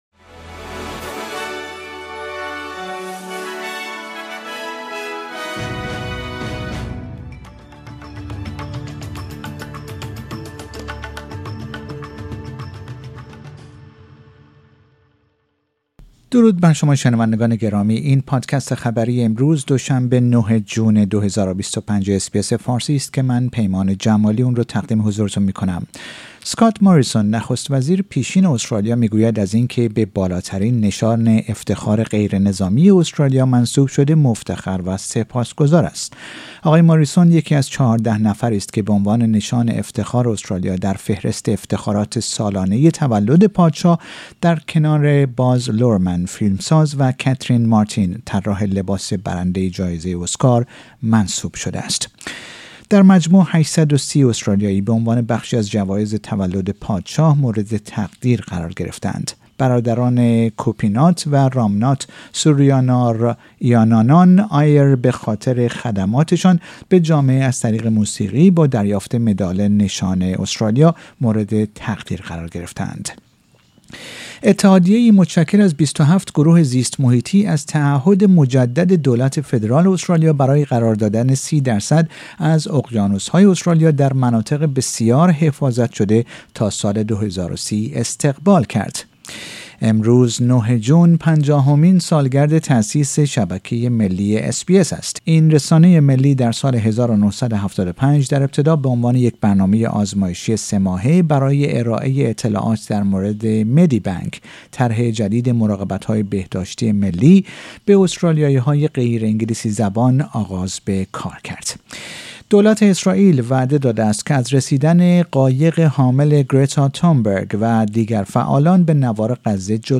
در این پادکست خبری مهمترین اخبار امروز دو شنبه ۹ جون ارائه شده است.